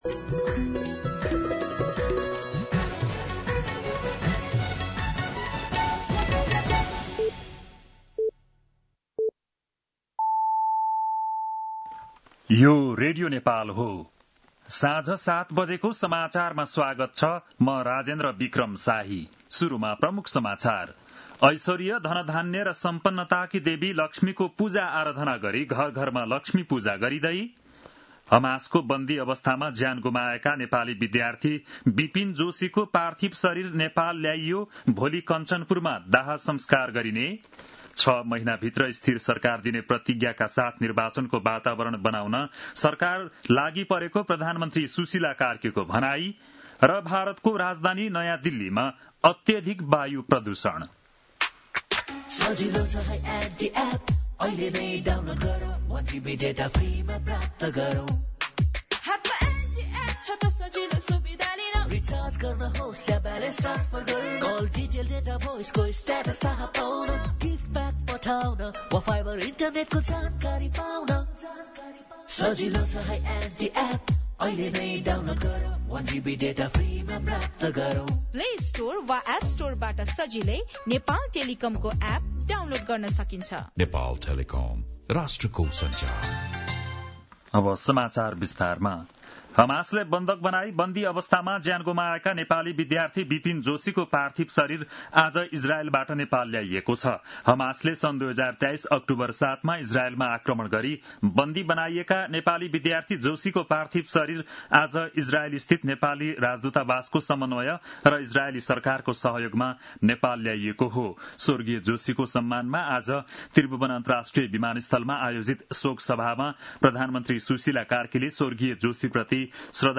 बेलुकी ७ बजेको नेपाली समाचार : ३ कार्तिक , २०८२
7-pm-nepali-news-7-03.mp3